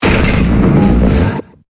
Cri de Golemastoc dans Pokémon Épée et Bouclier.